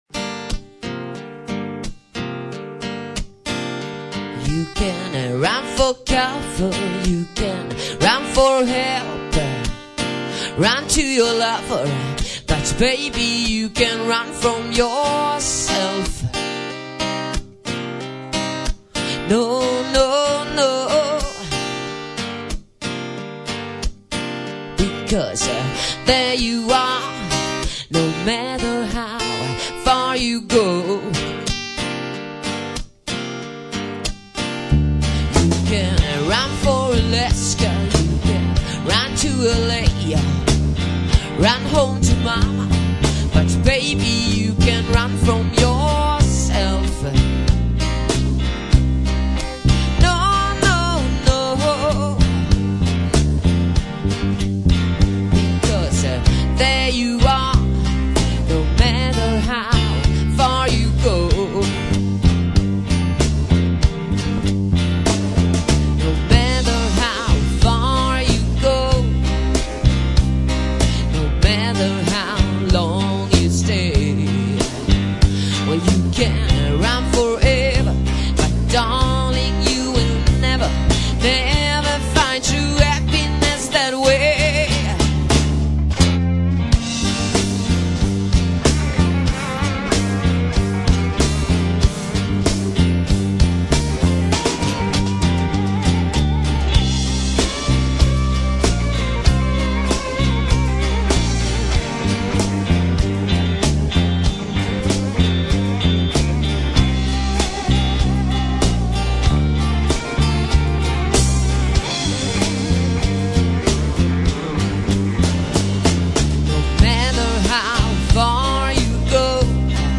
replokalsinspelningar